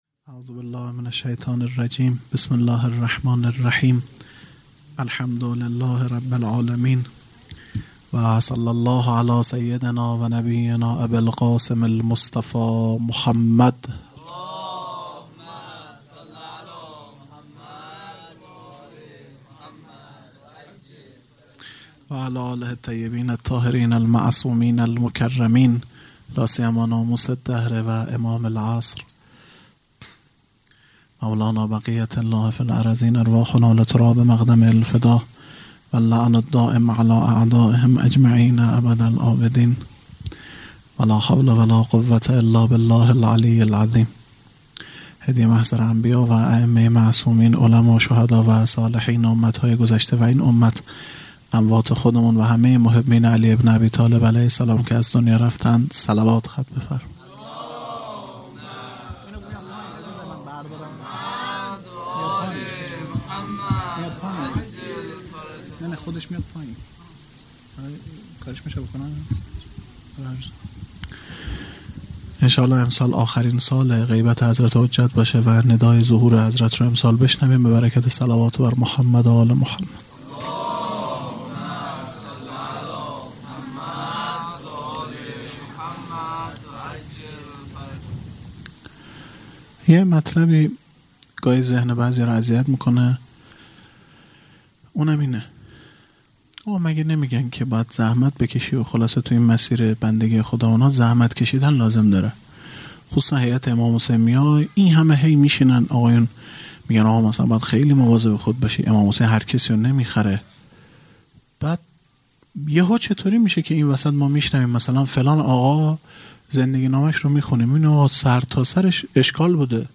خیمه گاه - هیئت بچه های فاطمه (س) - سخنرانی | ۱۲ خرداد ۱۴۰۱
جلسۀ هفتگی | ولادت حضرت معصومه(سلام الله علیها)